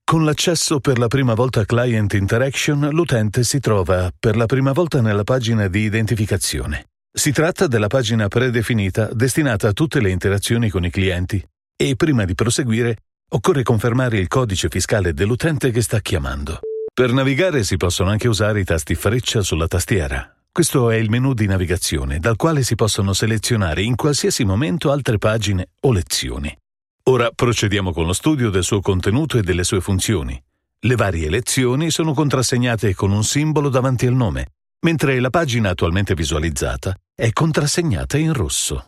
Weltweit bekannte Marken vertrauen ihm und er liefert professionelle Voiceover-Dienste mit Wärme, Klarheit und Schnelligkeit aus seinem hochmodernen Studio.
Erklärvideos
UAD Apollo X8, Mac Pro, U87Ai, TLM103, TLM67 und mehr.